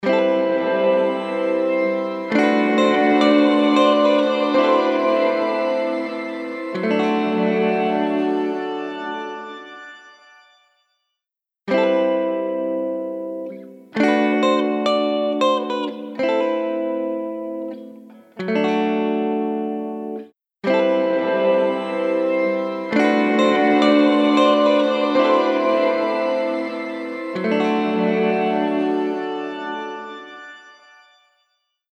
ピッチシフティングを融合した、幻想的なリバーブ
光へ向かって広がる、きらめきの残響
ShimmerVerb | Electric Guitar | Preset: Happy Trailing
ShimmerVerb-Eventide-Guitar-Happy-Trailing.mp3